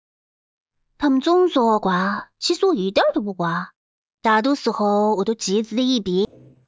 # optional: path to a prompt speech for voice cloning